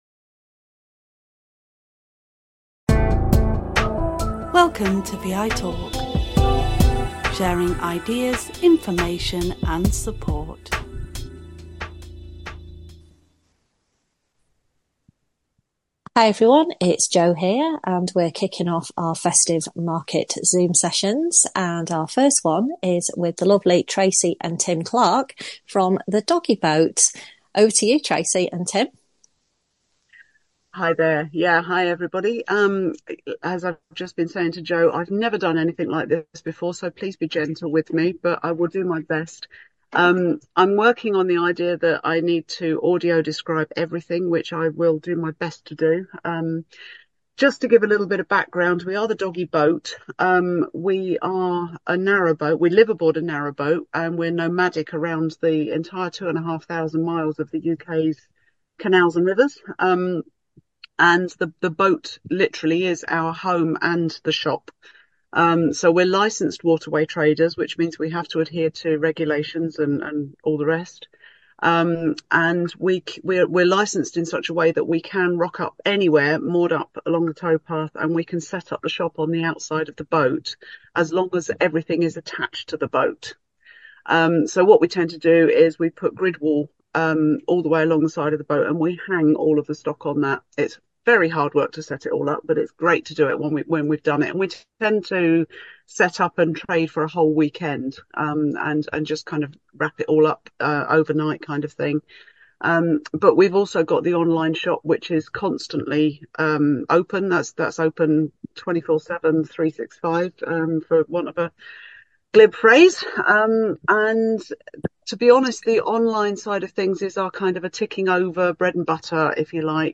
The first of our Festive Market presentations from The Doggie Boat.